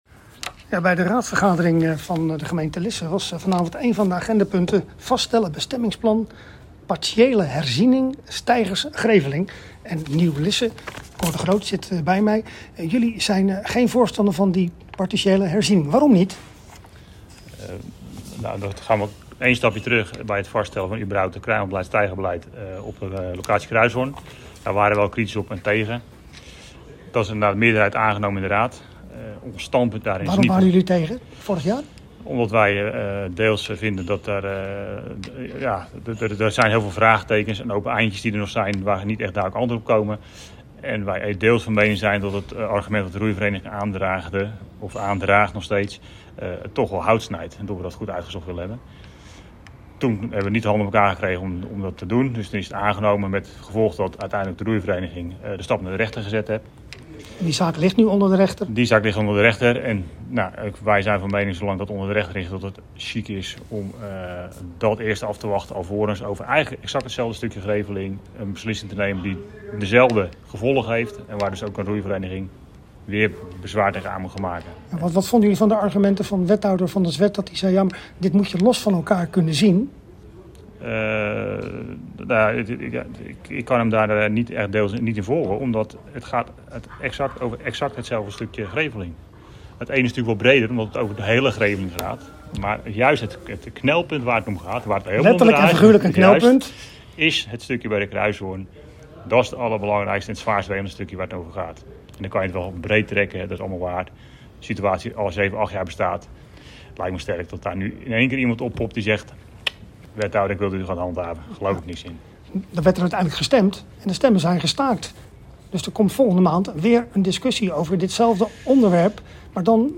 Hieronder interviews over dit onderwerp met Nieuw Lisse raadslid Cor de Groot en wethouder Kees van der Zwet: